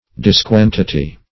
Search Result for " disquantity" : The Collaborative International Dictionary of English v.0.48: Disquantity \Dis*quan"ti*ty\, v. t. To diminish the quantity of; to lessen.
disquantity.mp3